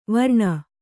♪ varṇa